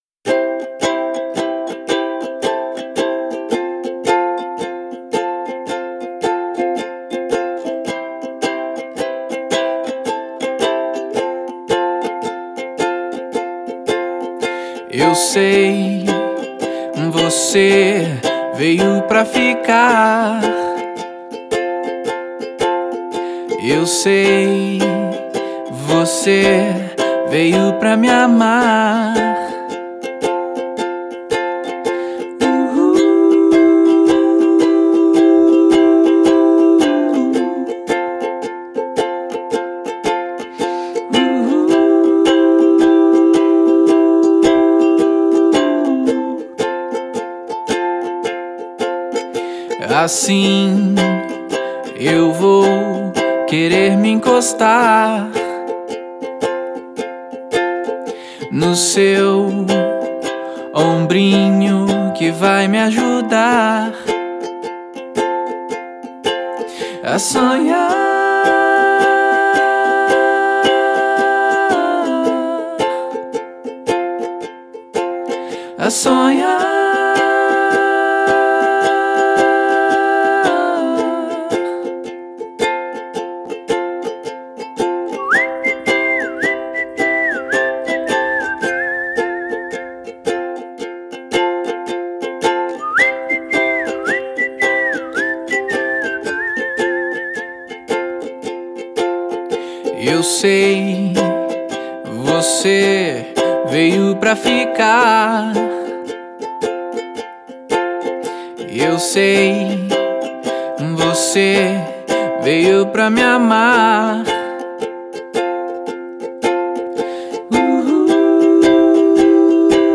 EstiloFolk